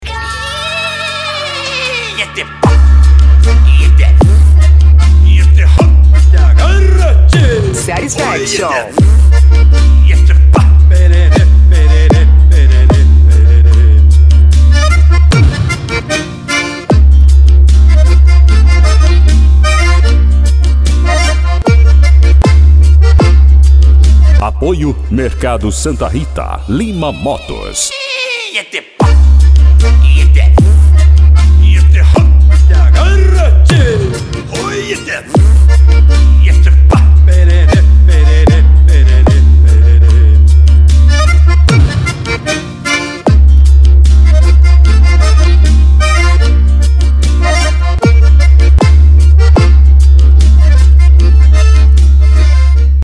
Bass
Funk
Mega Funk
Remix